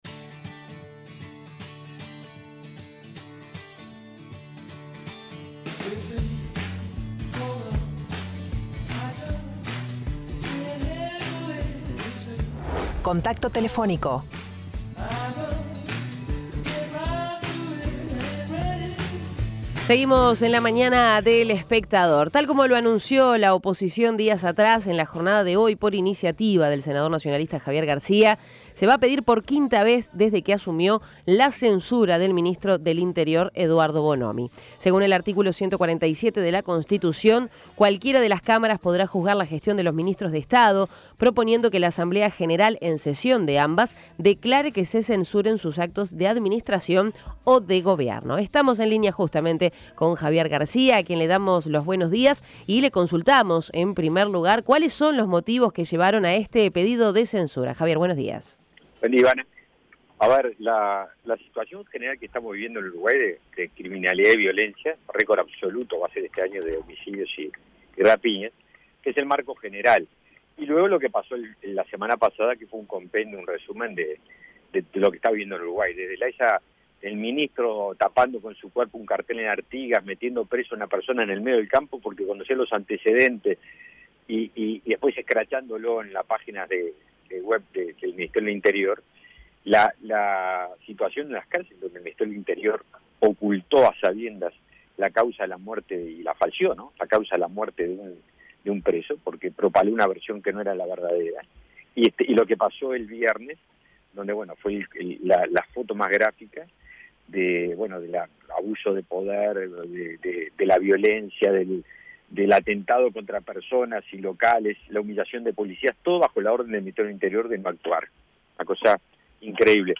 El senador del Partido Nacional, Javier García, explicó a La Mañana de El Espectador que este pedido de censura responde a la situación de inseguridad que está viviendo el país, a lo que se suman los casos en los que se vio inmerso el ministro.